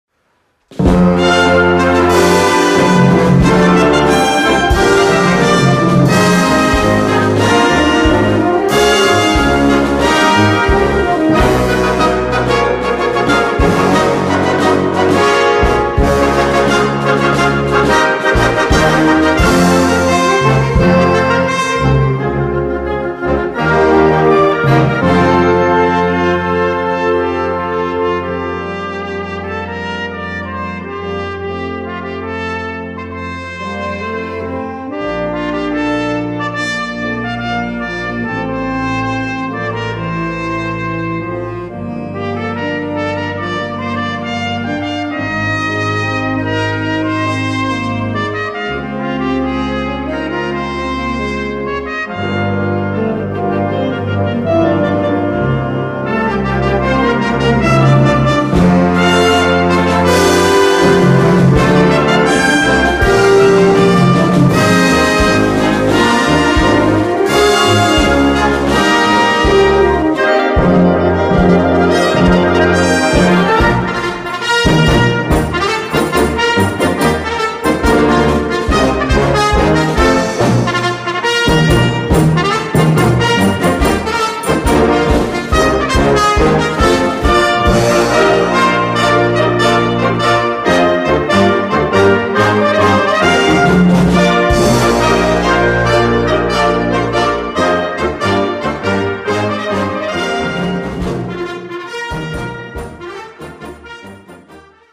Concert Overture
Gattung: Concert Band
5'29 Minuten Besetzung: Blasorchester PDF